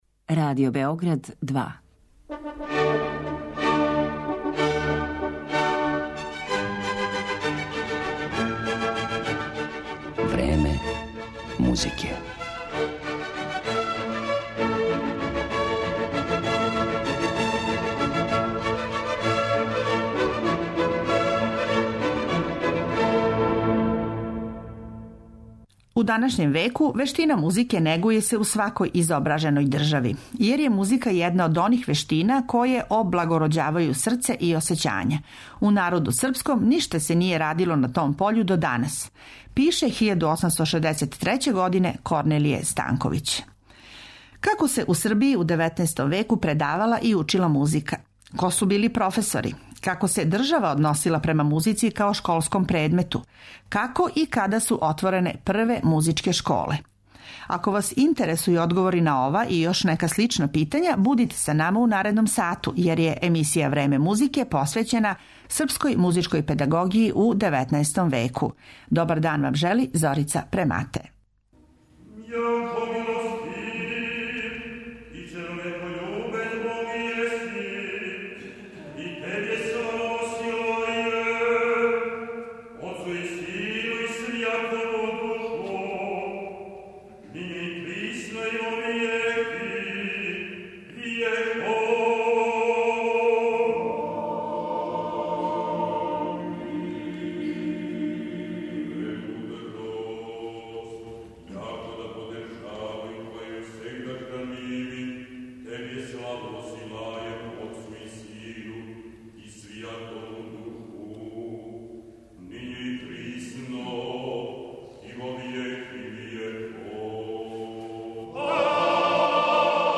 Биће емитована и ретко извођена дела домаће музичке баштине, одломци првих вишегласних литургија из пера Роберта Толингера и Франческа Синика, као и прве камерне композиције које је написао Јосип Шлезингер, а чућете и прву српску концертну увертиру, "Косово", из пера Даворина Јенка.